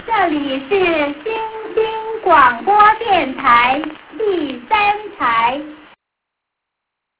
Each channel identifies itself at the beginning of the broadcasts as "the third" or "the fourth" program.